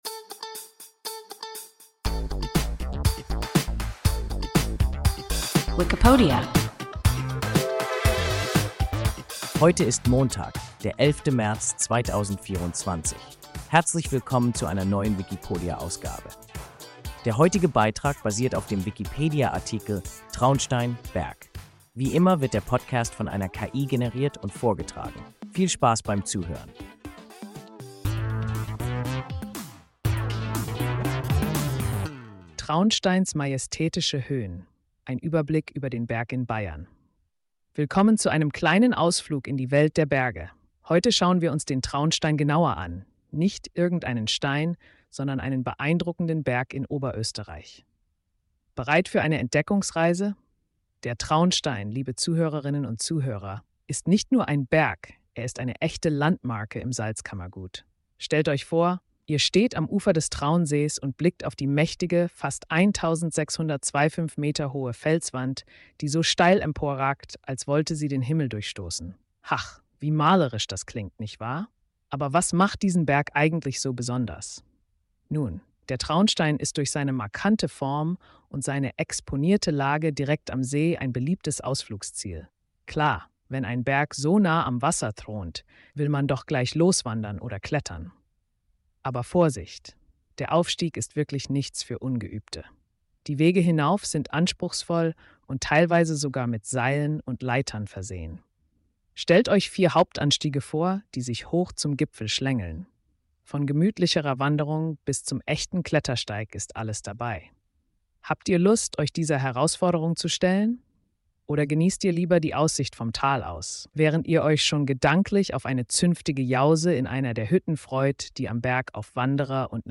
Traunstein – WIKIPODIA – ein KI Podcast